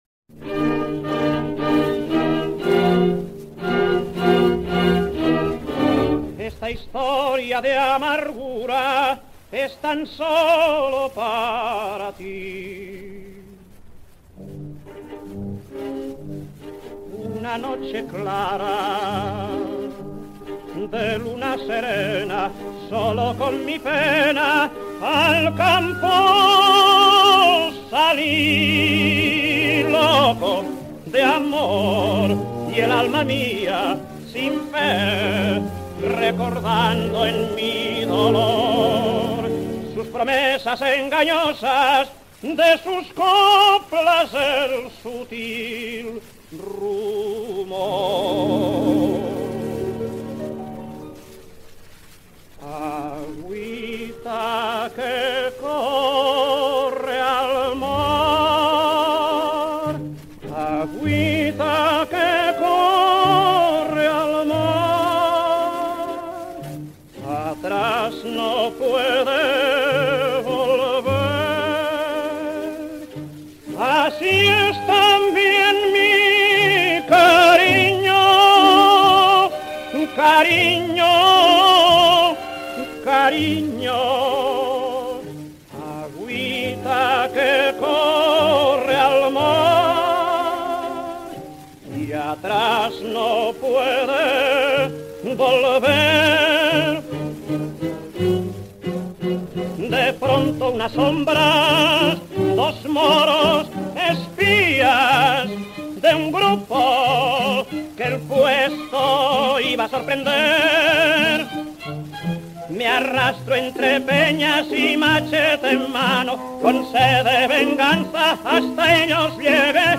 This largely forgotten zarzuela tenor had a good reputation at his time, and a career at least from the early 1920s into the 1960s.